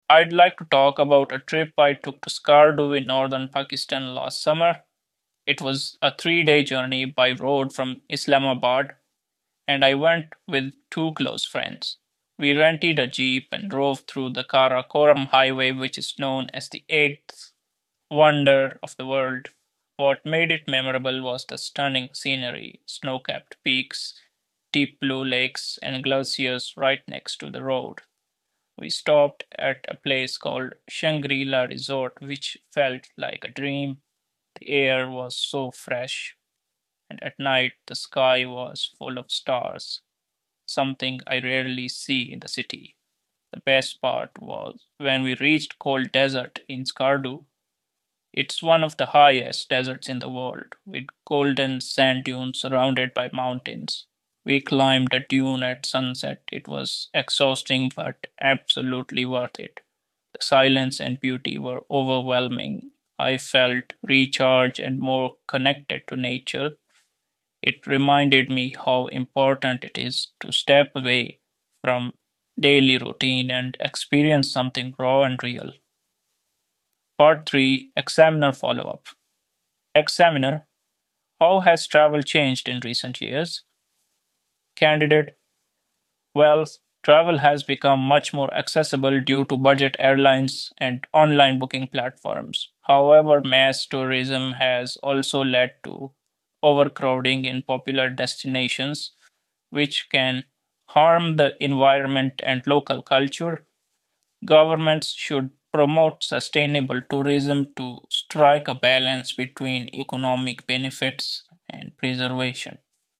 Speaking Part 2 Sample (Band 8.0)
ielts-speaking-band8.mp3